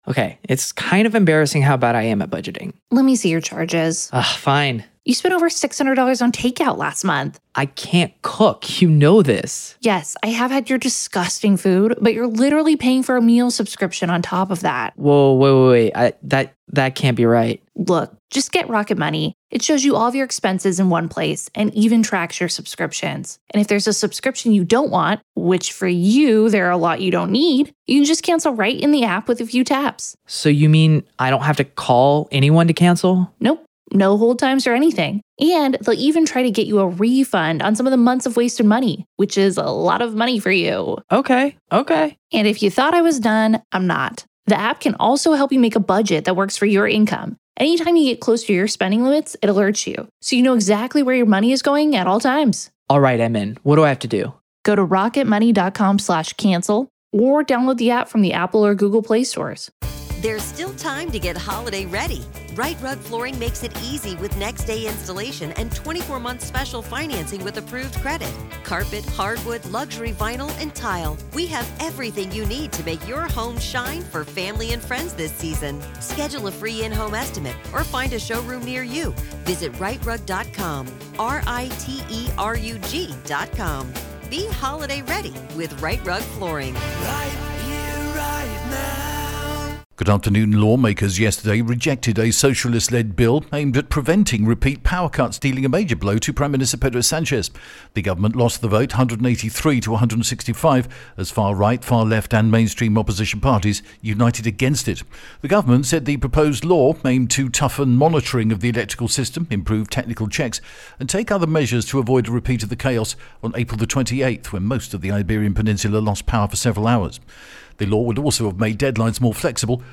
The latest Spanish news headlines in English: July 23rd 2025